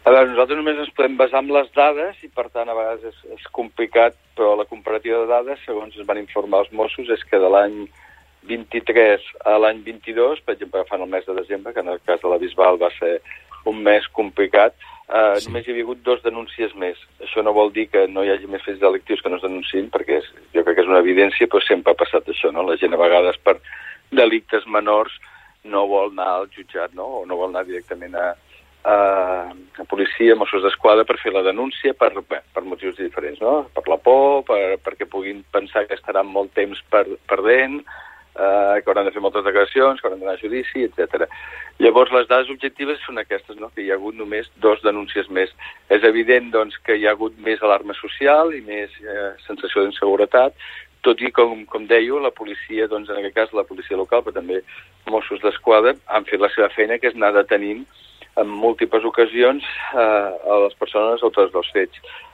I tot i la sensació d’inseguretat que es pot percebre, l’alcalde del municipi, Òscar Aparicio, en una entrevista al Supermatí reconeix que ells només “poden fixar-se en les dades, tot i que és complicat”.